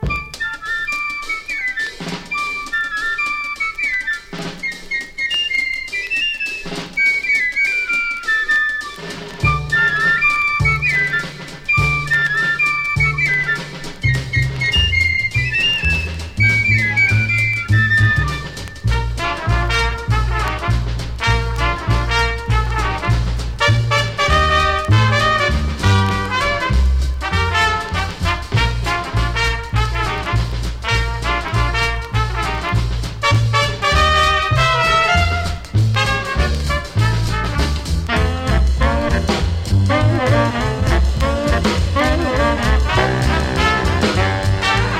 舞台音楽ということで、耳馴染みのあるメロディや叙情豊かな印象が響きます。
伸びやかにそしてマッドに、のんびりと聴かせたかと思えば狂熱のドラムも随所にと散りばめた好盤
Jazz, Stage & Screen　France　12inchレコード　33rpm　Stereo